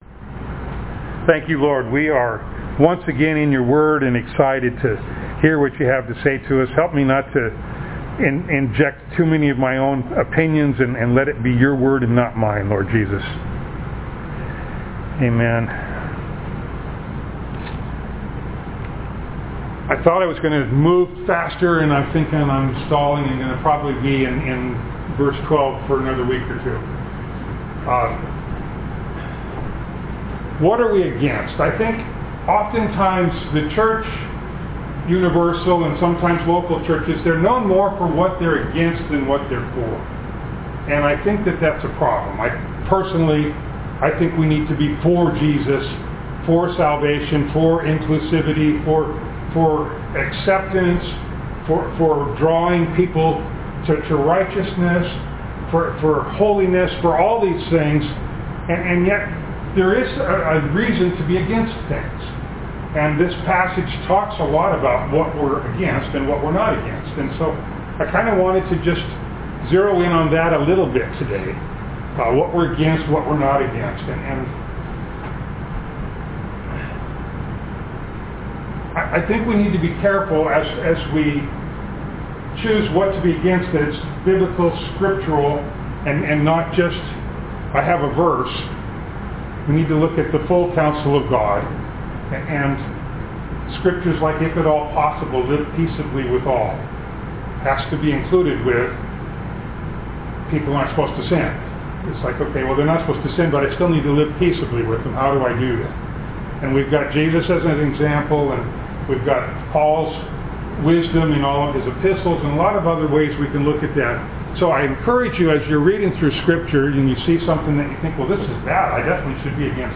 Ephesians 6:12 Service Type: Sunday Morning Download Files Notes « Our Struggle Rulers